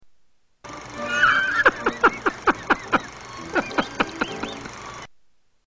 Gideon's laugh
Category: Television   Right: Personal